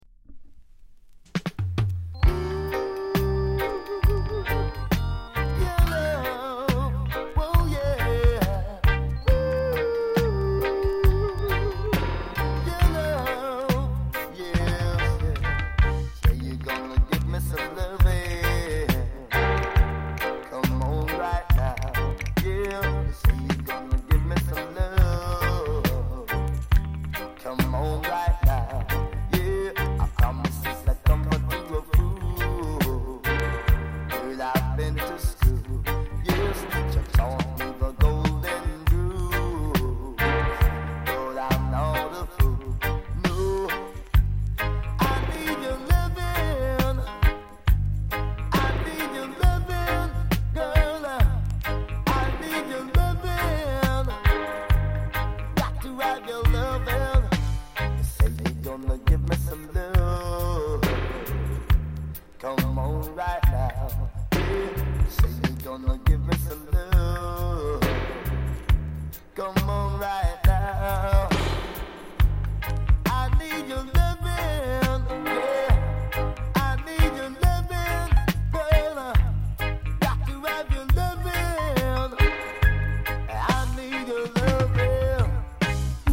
高品質 ONE DROP～ROOTS